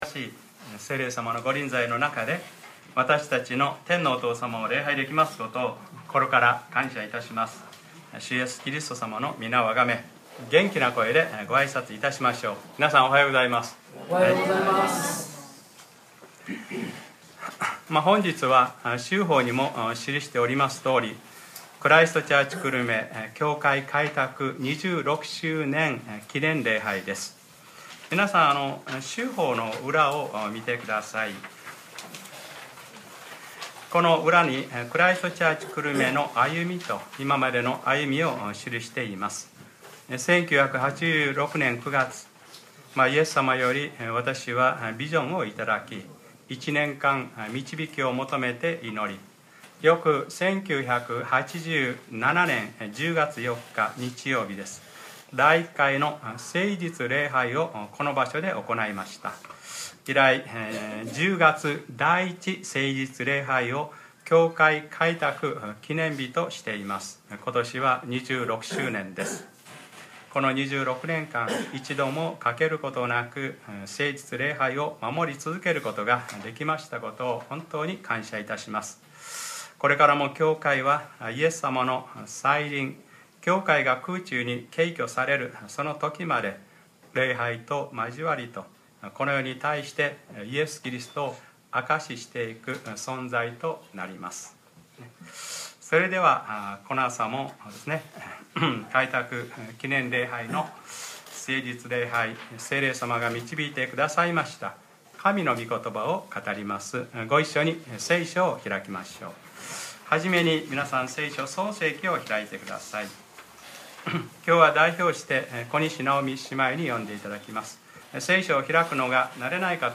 2013年10月06日(日）礼拝説教 『ずっと私の羊飼いであられた神』